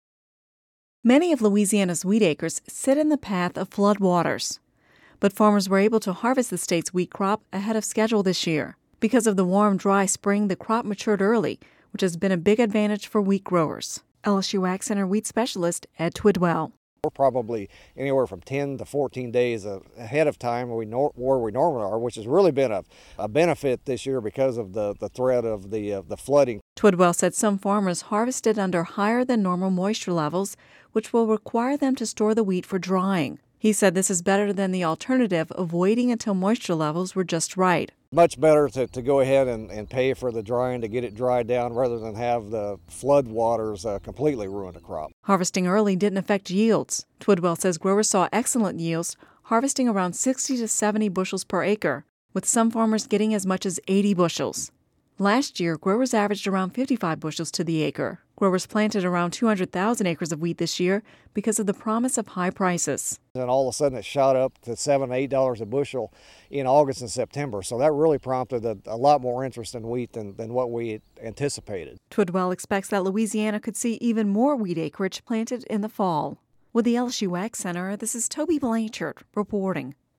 (Radio News 05/12/11) Many of Louisiana’s wheat acres sit in the path of flood waters, but farmers were able to harvest the state’s wheat crop ahead of schedule this year. Because of the warm, dry spring, the crop matured early which has been a big advantage for wheat growers.